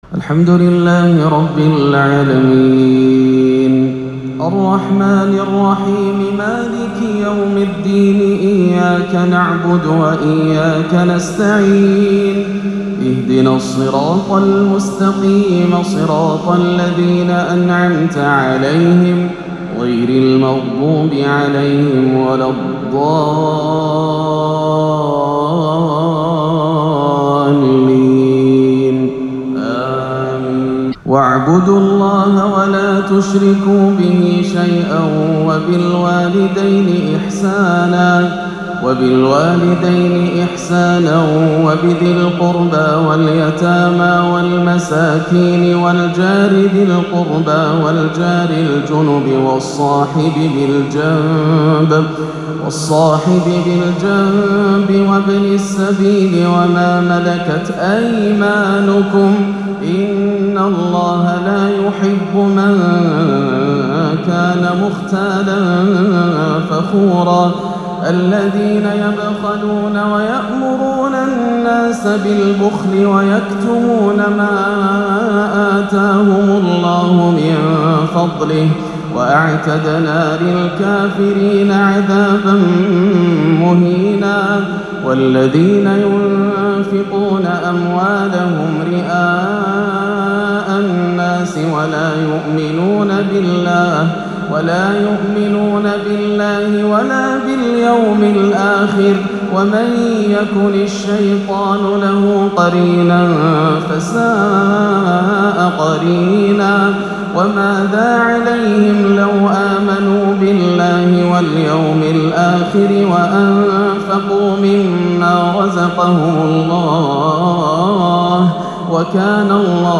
(كُلَّمَا نَضِجَتْ جُلُودُهُم بَدَّلْنَاهُمْ جُلُودًا غَيْرَهَا لِيَذُوقُوا الْعَذَابَ) تلاوة ابداعية مذهلة من سورة النساء - الأحد 13-8 > عام 1439 > الفروض - تلاوات ياسر الدوسري